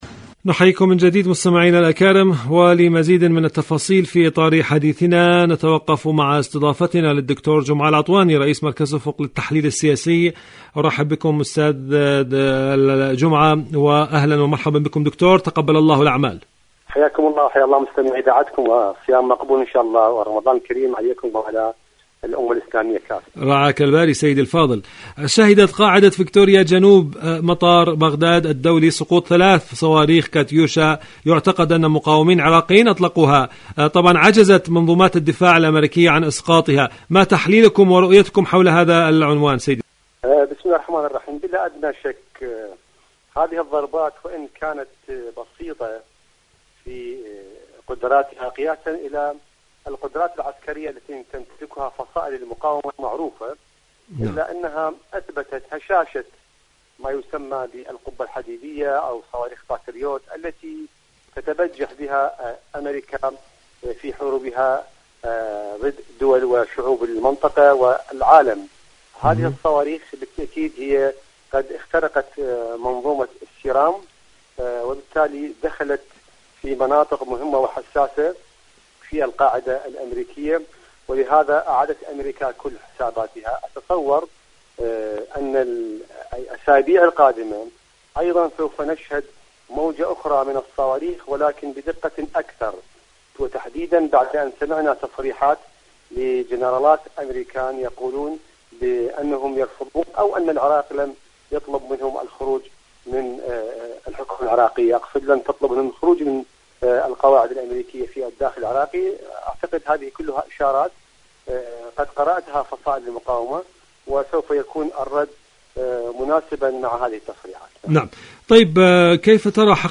مقابلات